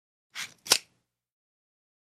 Звук щелчка автомобильного ремня безопасности